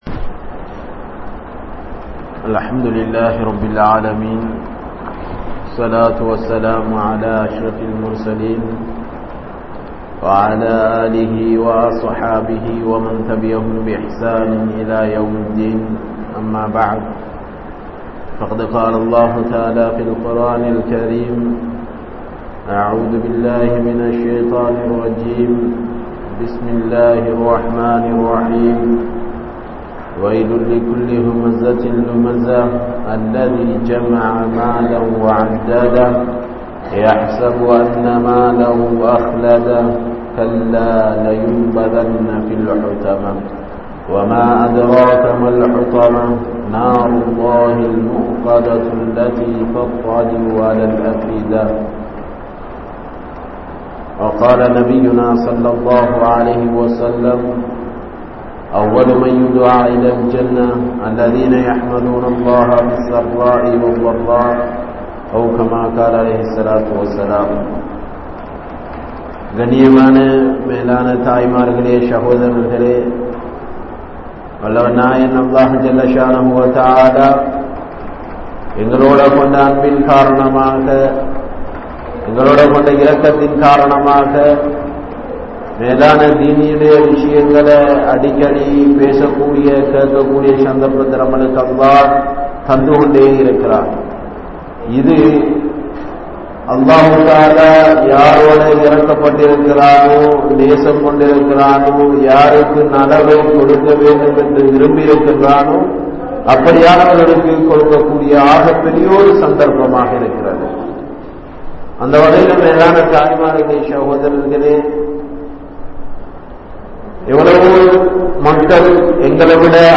Thiyaahamum Indraya Maslimkalum (தியாகமும் இன்றைய முஸ்லிம்களும்) | Audio Bayans | All Ceylon Muslim Youth Community | Addalaichenai